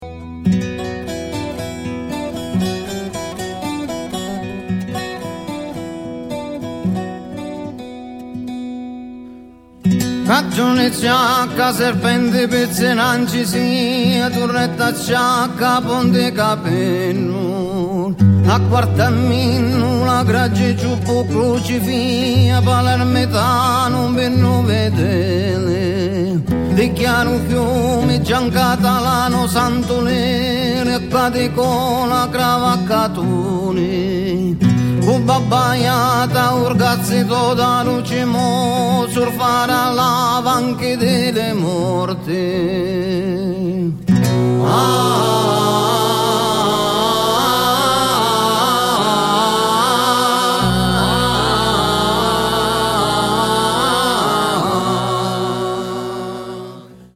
guitarra clasica, saz baglama, saz yura, voz
voz, harmonium
en el Museo de la Música de Urueña